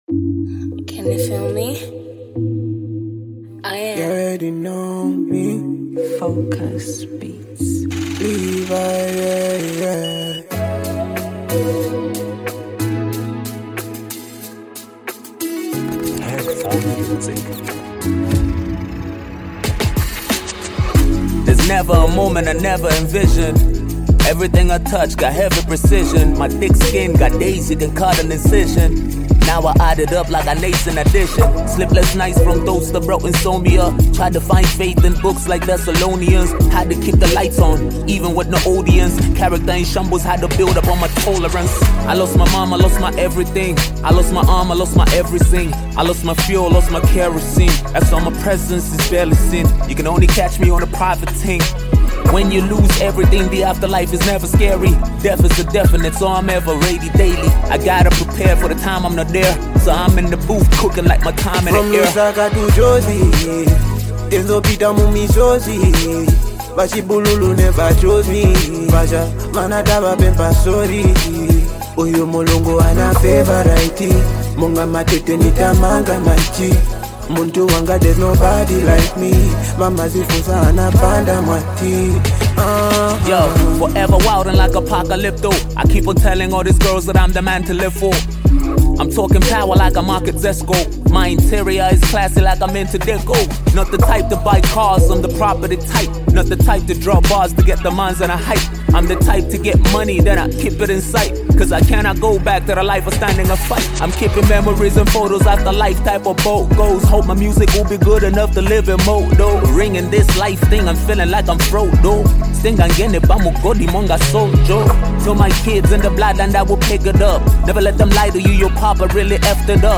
signature vocal flair
smooth Afrobeat rhythms